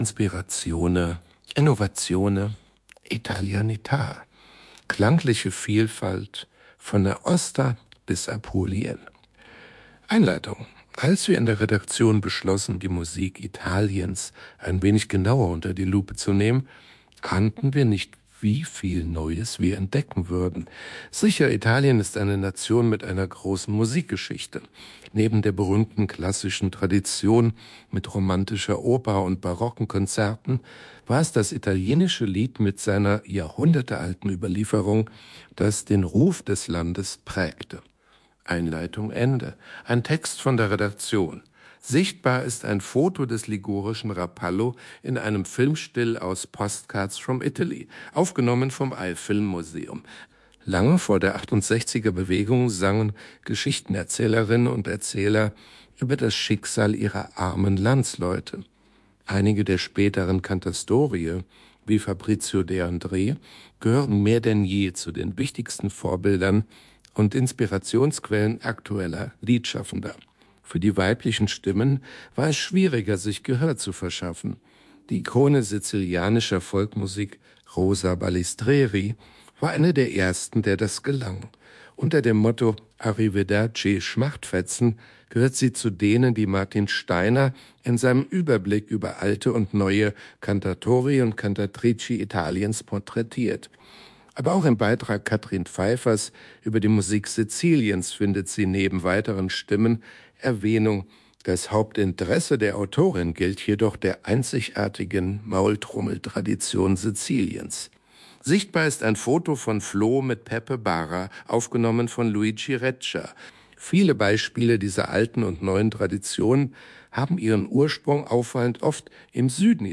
Die Stimme dieser Lesung wurde freundlicherweise zur Verfügung gestellt und darf ohne ausdrückliche Genehmigung nicht weiterverwendet werden.